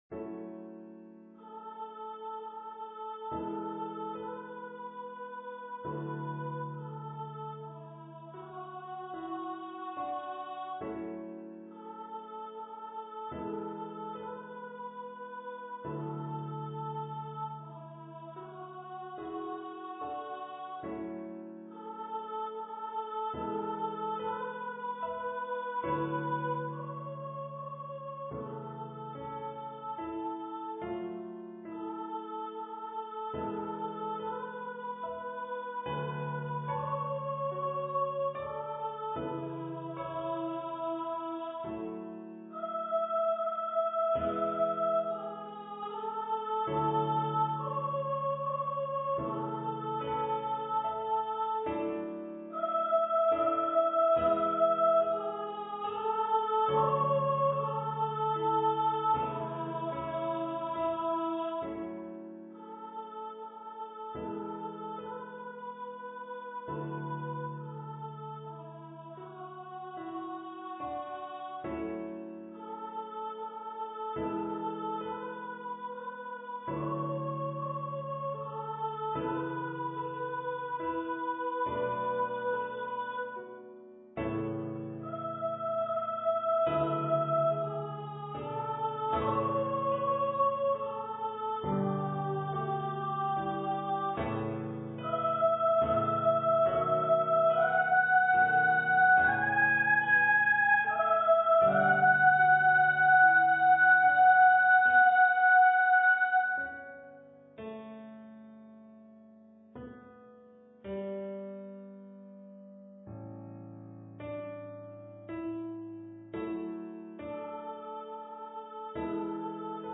for solo voice and piano
Voice solo (with accompaniment)